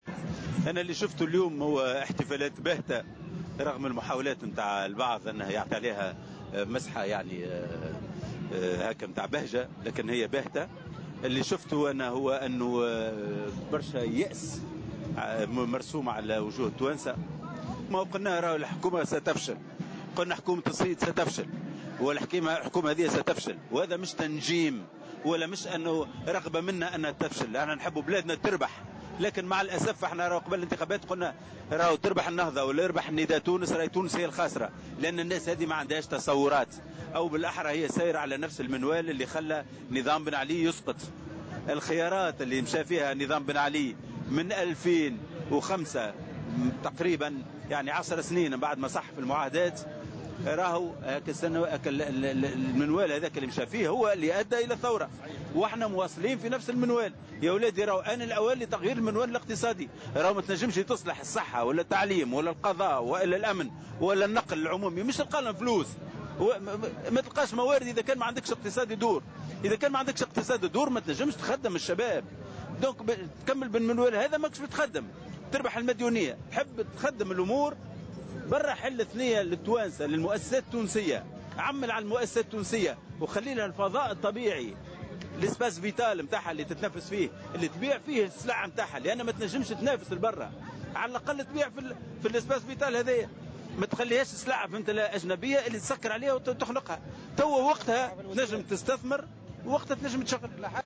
وقال المرايحي في تصريح لمراسل "الجوهرة أف أم" إن حكومة يوسف الشاهد ستفشل على غرار حكومة الحبيب الصيد لأنها أبقت على نفس المنوال التنموي الذي أسقط نظام بن علي.